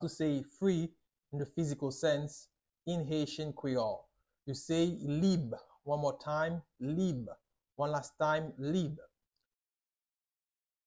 11.How-to-say-Free-physical-in-Haitian-Creole-–-Lib-with-pronunciation.mp3